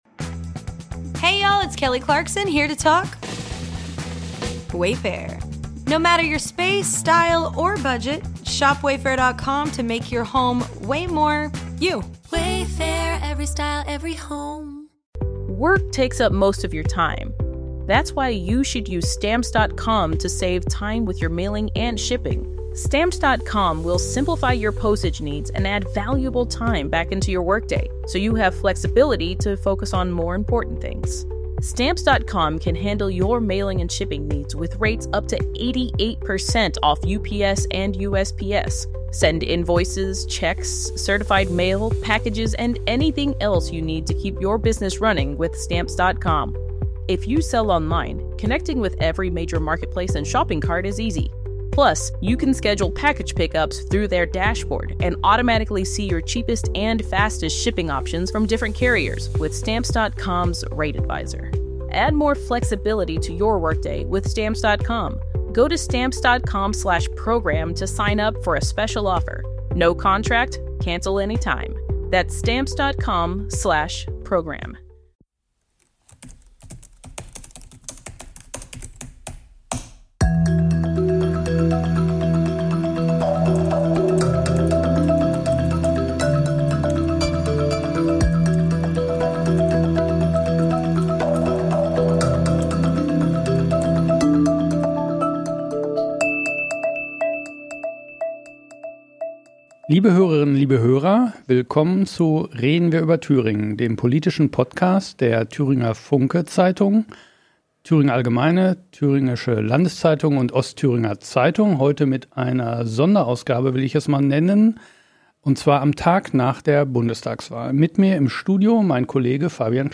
Dass wir vor einer Wahl Interviews mit den Spitzenkandidaten führen, hat Tradition. Neu ist in diesem Jahr: Die aufgezeichneten Gespräche werden vollständig zum Nachhören als Podcast bereitgestellt.